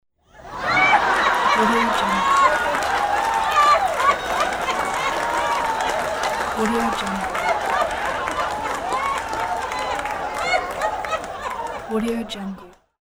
دانلود افکت صوتی خنده و تشویق تماشاگران